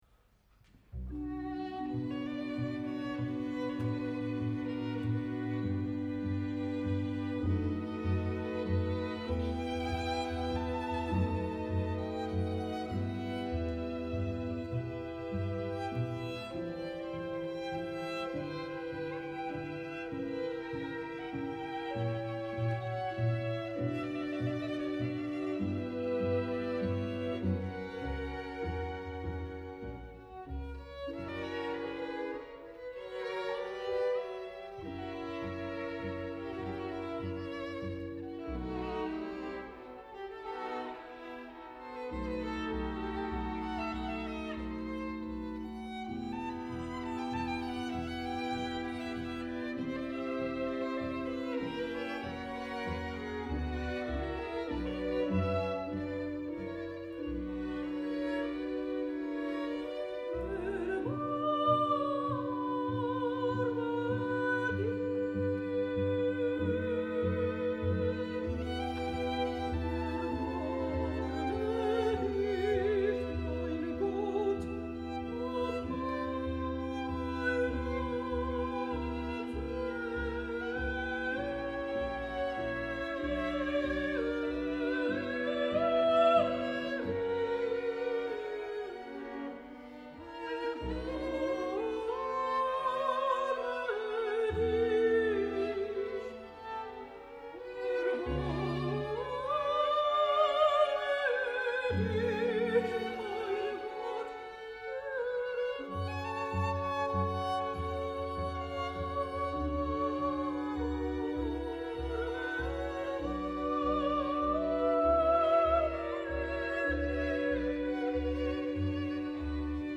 横浜マタイ研究会合唱団
２０１６年４月３０日　神奈川県立音楽堂　　曲目　マタイ受難曲　第二部　J.S.Bach